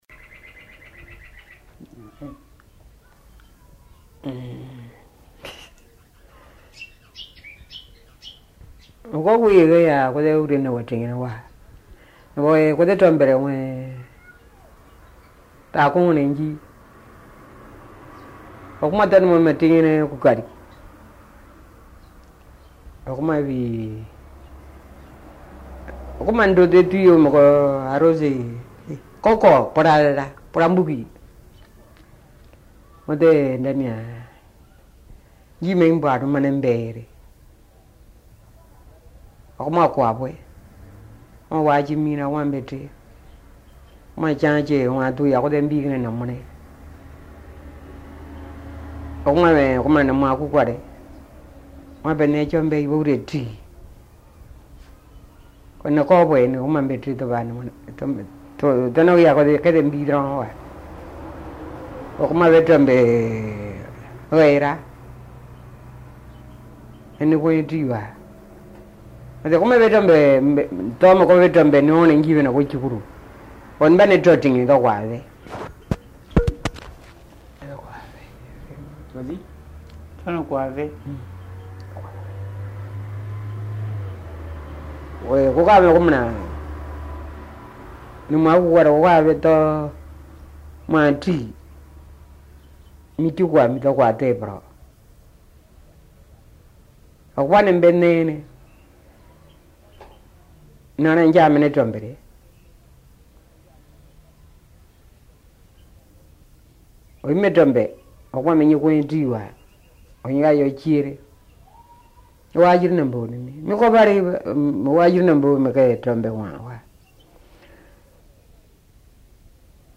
Documents joints Dialogue leçon 11 ( MP3 - 2.2 Mio ) Un message, un commentaire ?